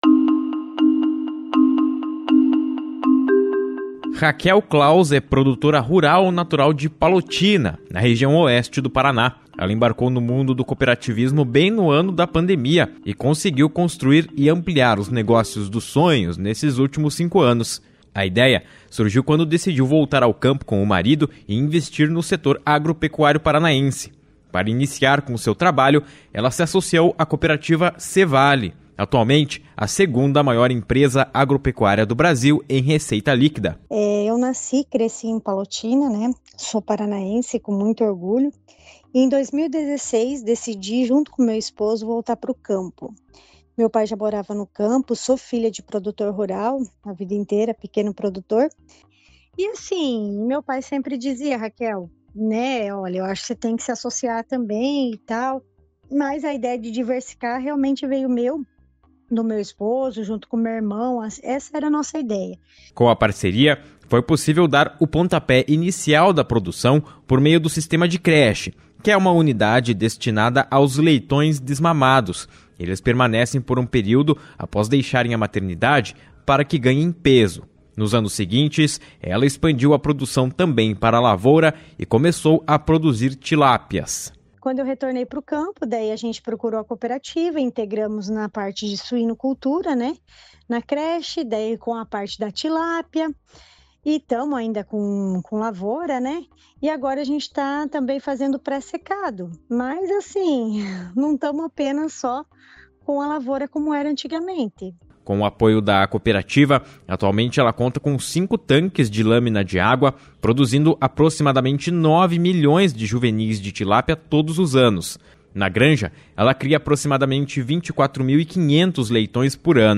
Reportagem 2 – Quando precisamos recomeçar